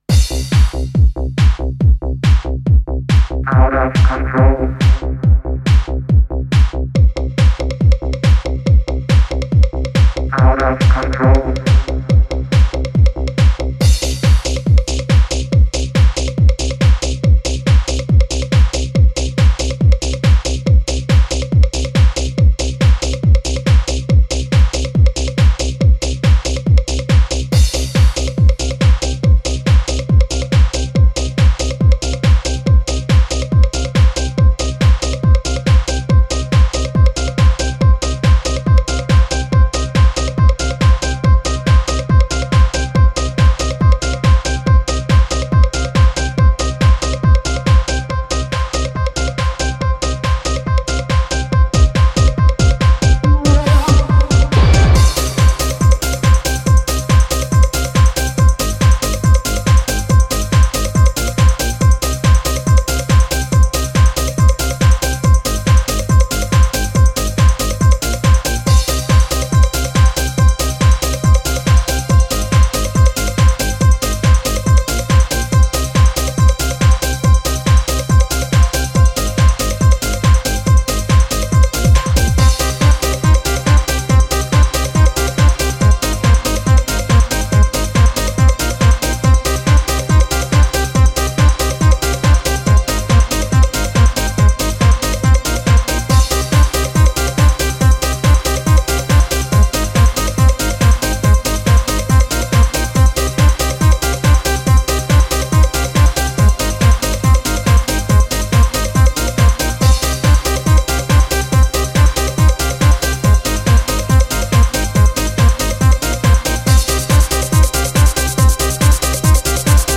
Жанр: HardTrance